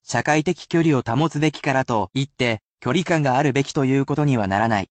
I read these aloud for you, as well, but you can use this as a useful opportunity to practise your reading skills.